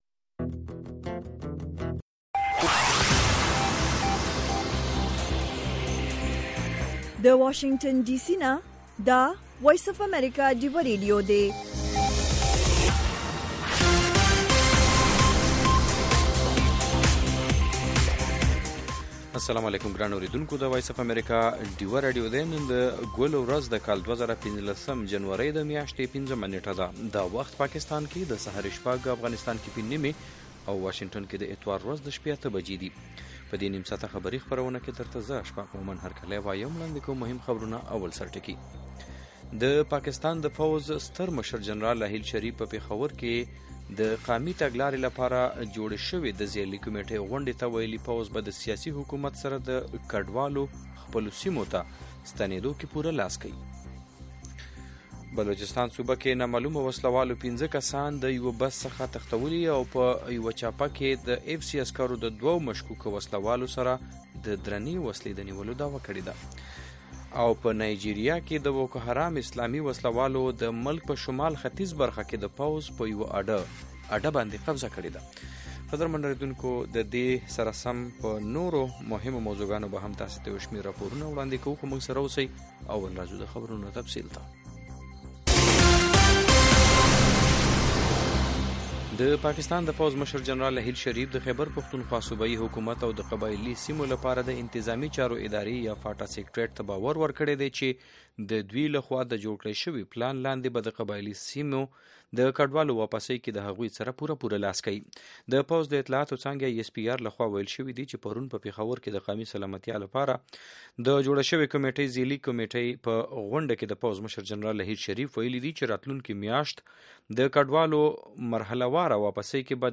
خبرونه
د وی او اې ډيوه راډيو سهرنې خبرونه چالان کړئ اؤ د ورځې د مهمو تازه خبرونو سرليکونه واورئ.